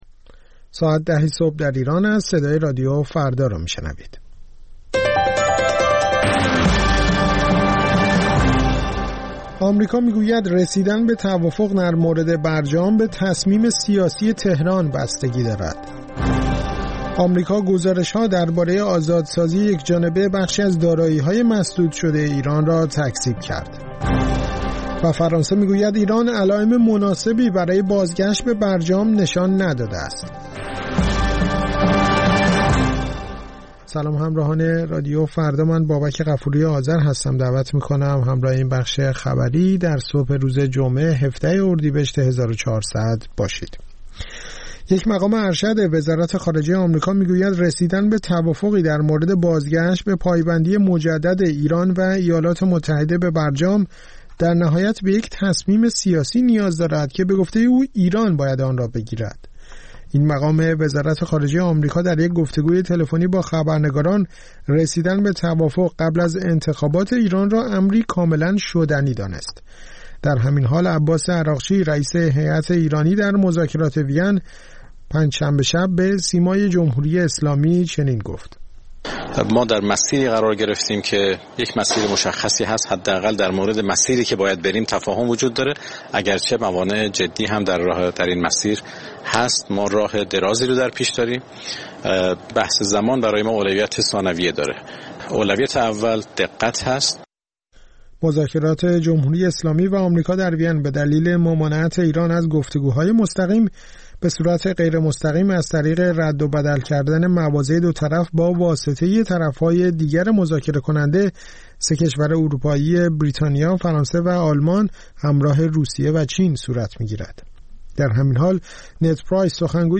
اخبار رادیو فردا، ساعت ۱۰:۰۰